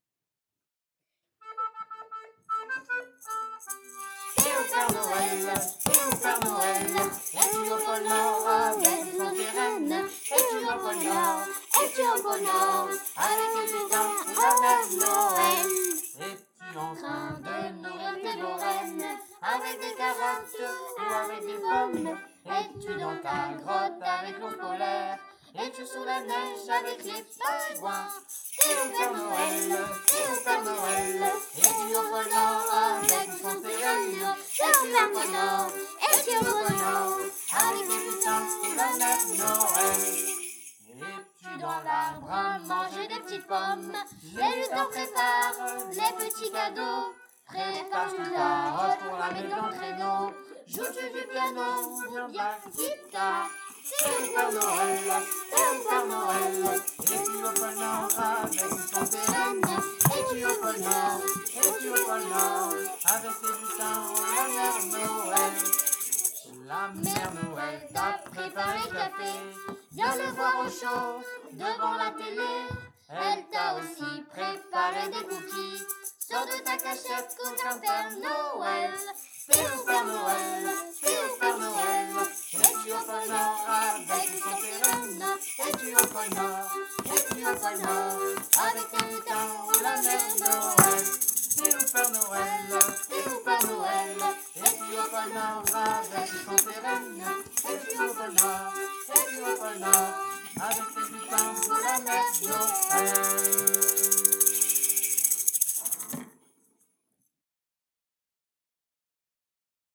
Un nouveau titre publié en single par un atelier du centre de loisir !